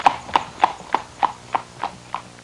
Horse Galloping Away Sound Effect
Download a high-quality horse galloping away sound effect.
horse-galloping-away.mp3